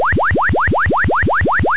Sweep, Chirp,     gleitende Frequenzänderung
Abb. 01: Sweep, die Frequenz wird gleitend verstellt. Bei schneller Frequenzänderung ist die Änderung nicht mehr bewußt wahrnehmbar.
generator-sweep-mono-28-2.wav